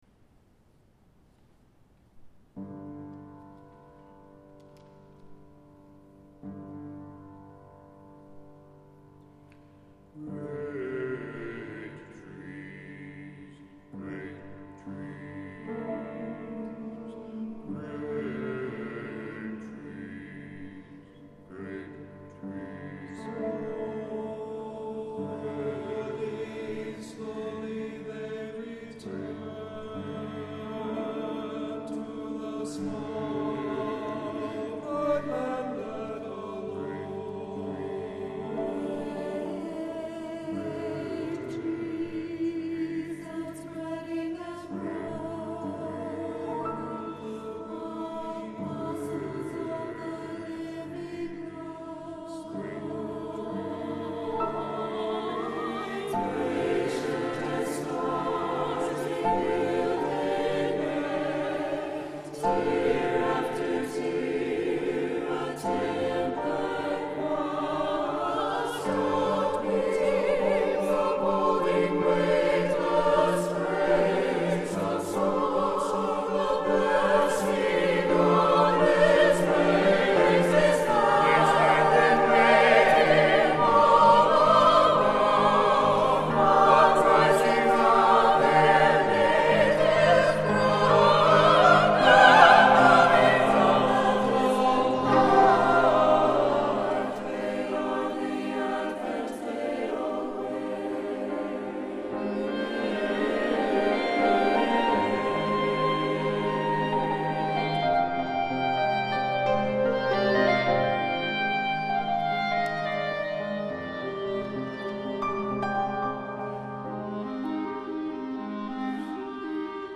for SATB Chorus, Clarinet, and Piano (2009)
These songs are scored for SATB chorus.